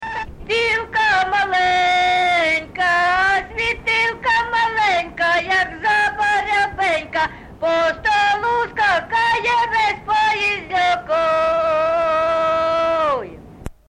ЖанрВесільні
Місце записус. Харківці, Миргородський (Лохвицький) район, Полтавська обл., Україна, Полтавщина